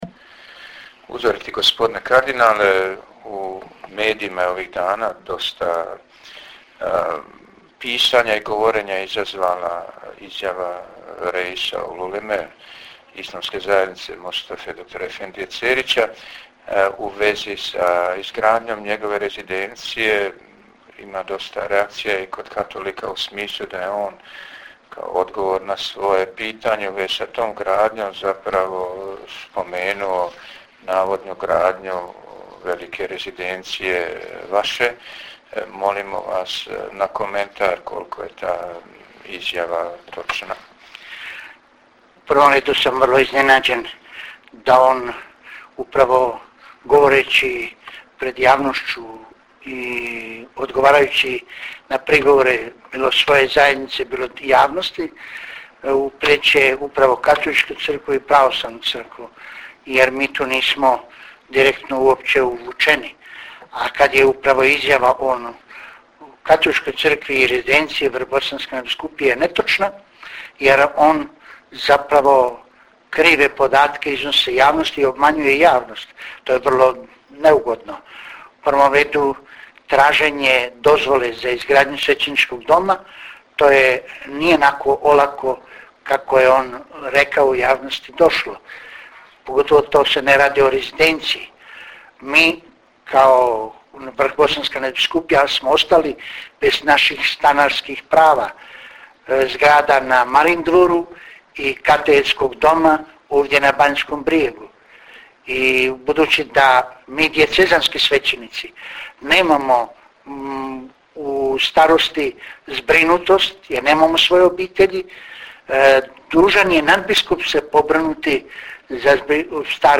Intervju s nadbiskupom metropolitom vrhbosanskim kardinalom Vinkom Puljićem